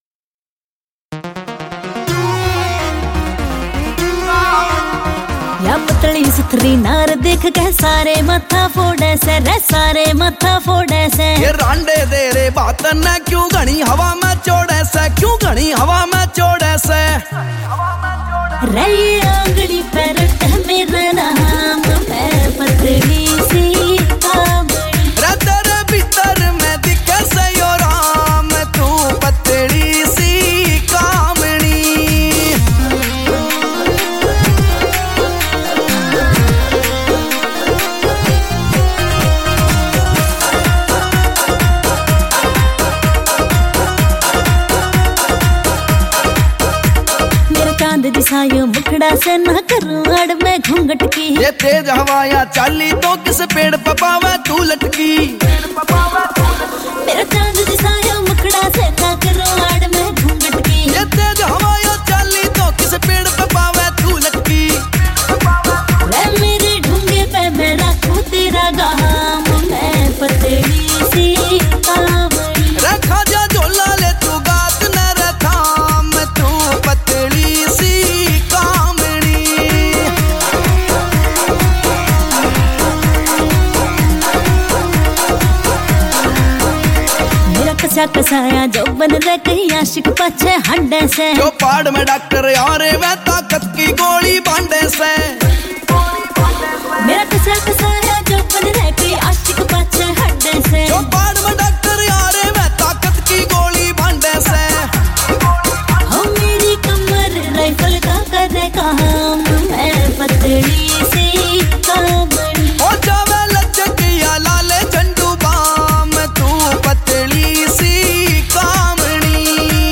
Category: Haryanvi Songs